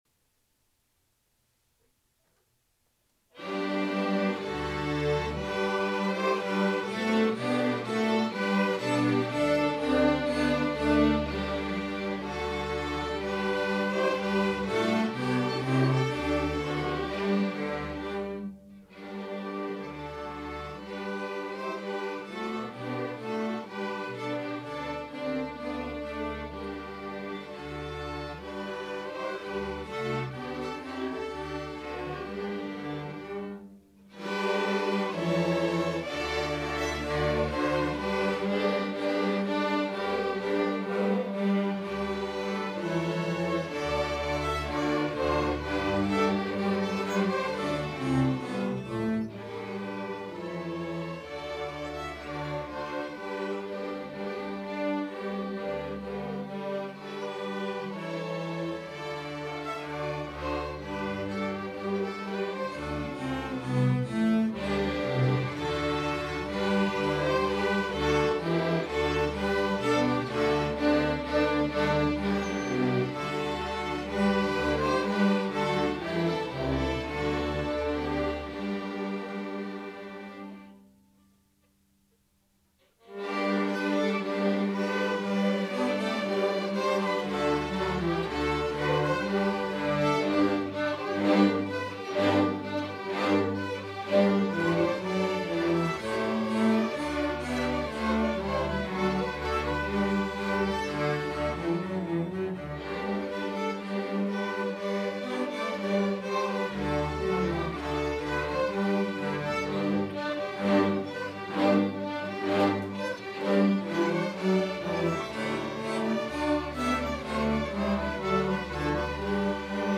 The string ensemble was very successful at the local music festival competition, scoring excellent results which entitled them to compete at the next level of the music festival.
The recording was completed on a laptop running Sonar Software and equipped with a Pocket VX digital interface which connects to a mixer, in this case with 2 Shure microphones.
Enjoy listening to the student recordings: